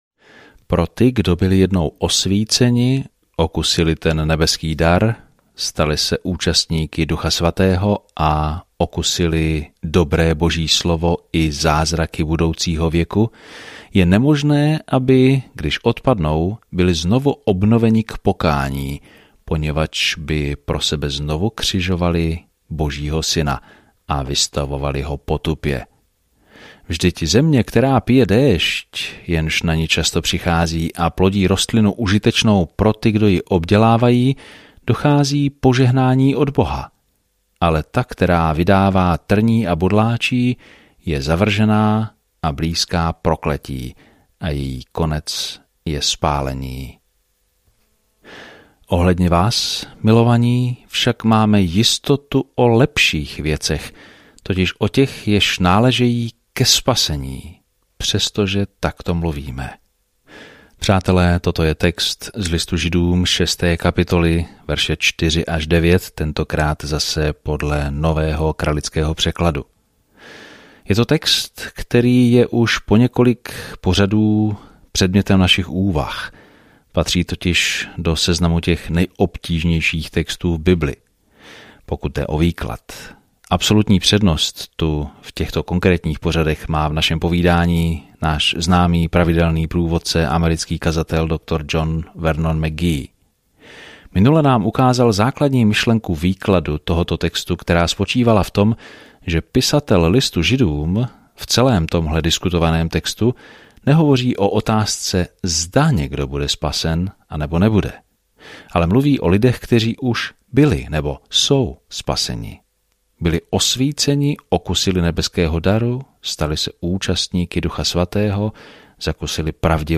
Denně procházejte Hebrejcům, když posloucháte audiostudii a čtete vybrané verše z Božího slova.